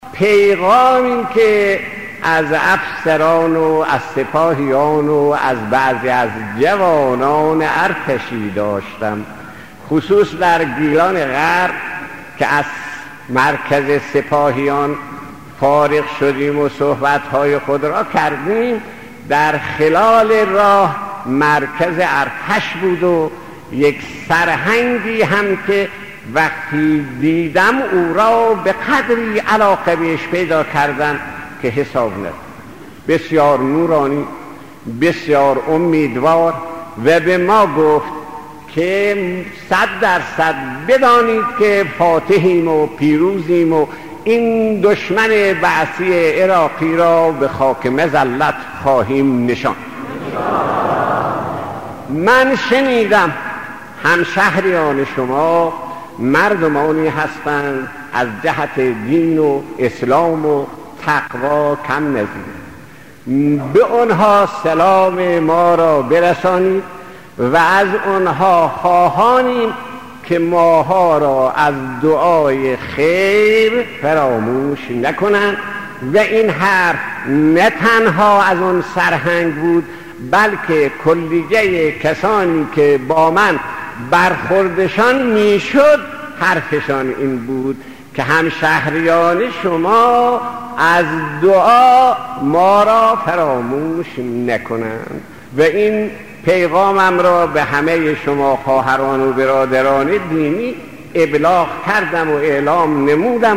صوت/ سخناني از شهيد آيت الله صدوقى پيرامون التماس دعاي رزمندگان از مردم يزد